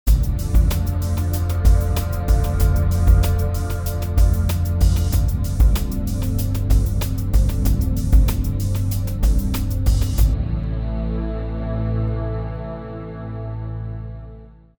Home > Music > Beats > Medium > Dreamy > Laid Back